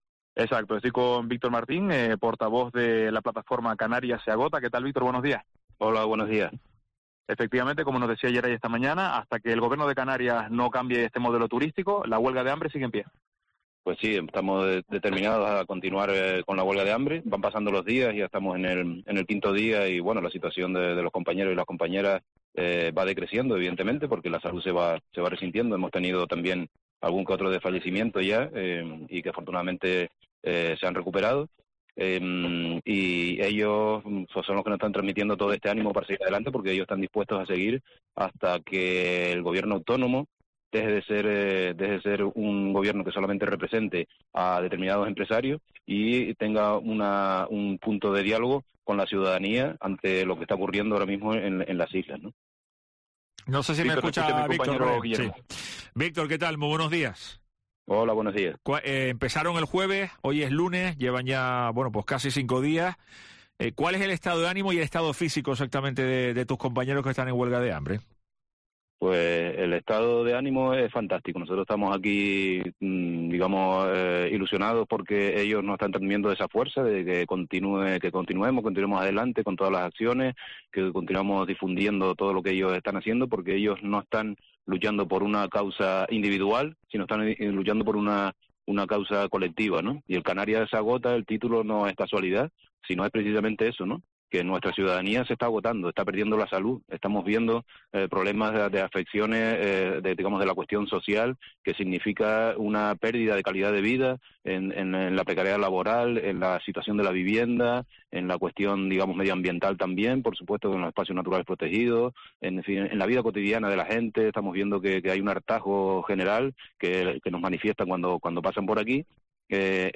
El vicepresidente del Cabildo Lope Afonso cree es una postura "desproporcionada y muy desafortunada" y les anima "a que abandonen esta situación y busquen otras fórmulas"